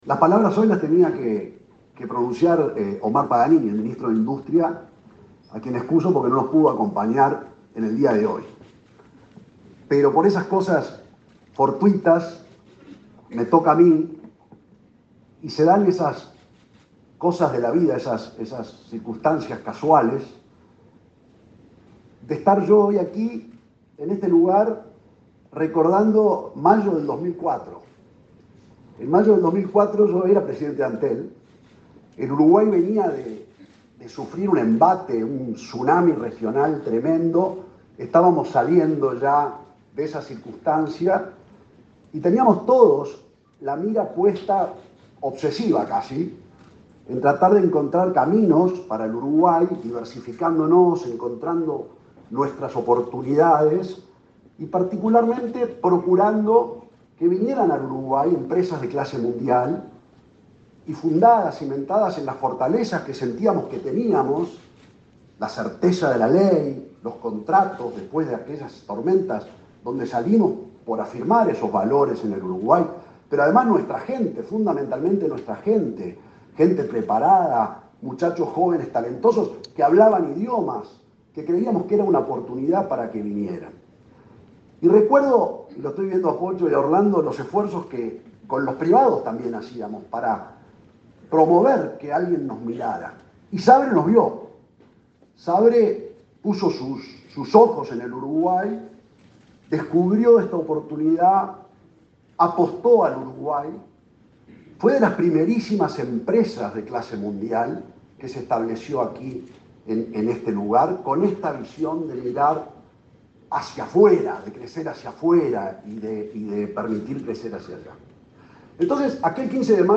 Palabras del presidente de Antel, Gabriel Gurméndez
El presidente de Antel, Gabriel Gurméndez, participó, este martes 11, en la inauguración de la empresa Sabre Uruguay en Zonamérica.